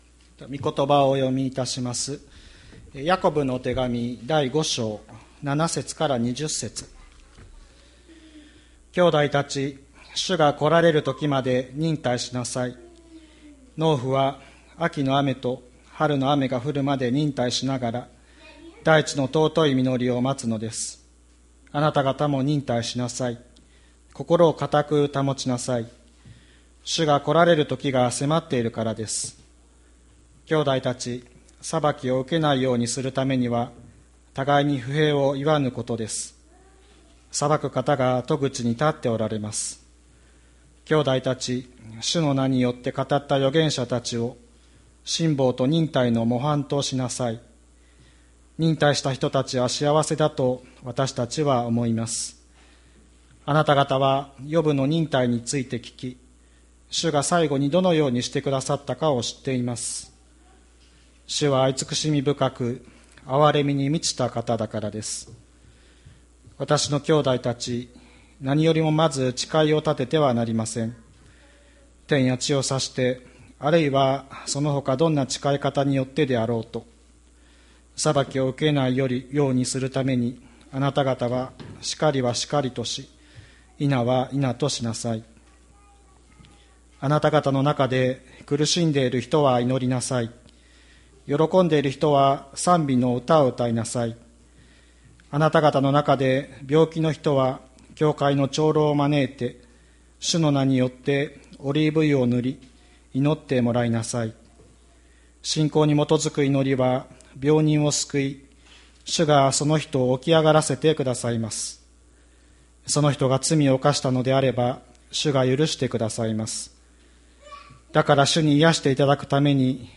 2020年06月07日朝の礼拝「祈りつつ、望みに生きる」吹田市千里山のキリスト教会
千里山教会 2020年06月07日の礼拝メッセージ。